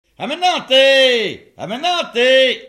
Cris pour rentrer les vaches
Mareuil-sur-Lay
Appels de bergers, ranz des vaches
couplets vocalisés